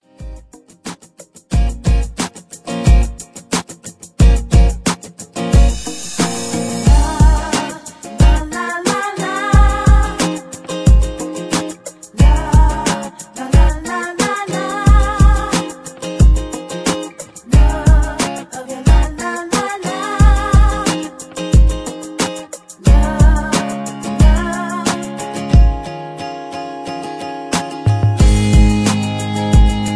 Karaoke Mp3 Backing Tracks
karaoke